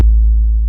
French_808.wav